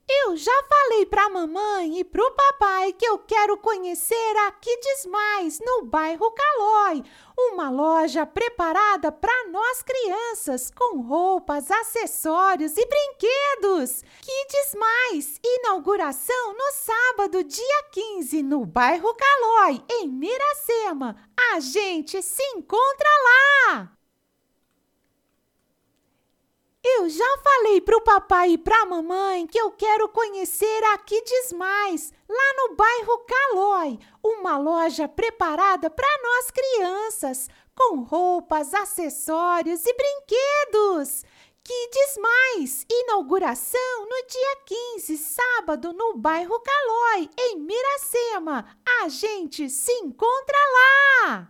infantil: